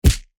face_hit_small_23.wav